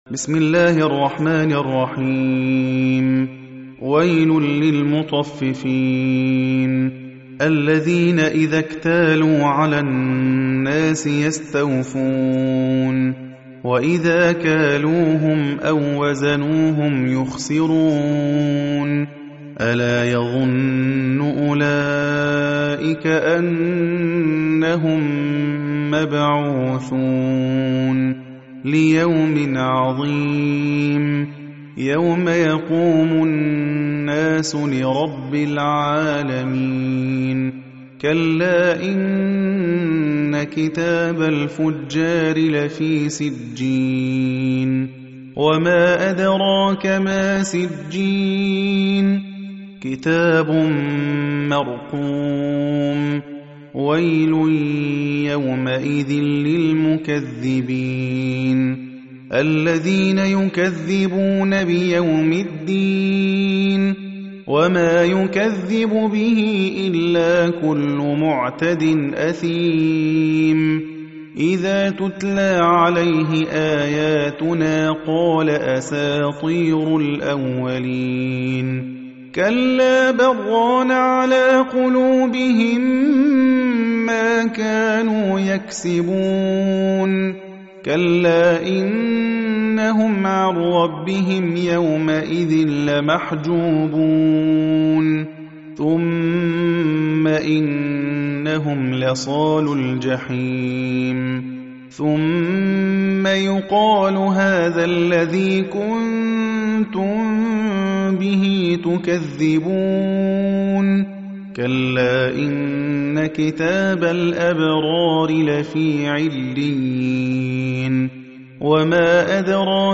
Riwayat Hafs